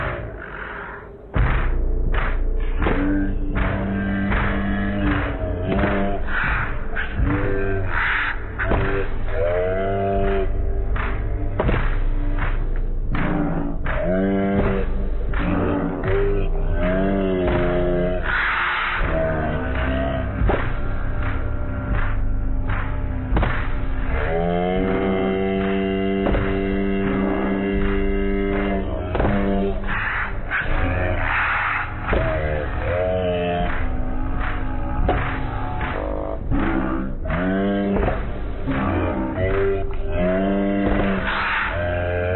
que j’ai rééchantillonné de 44100 à 11025 Hz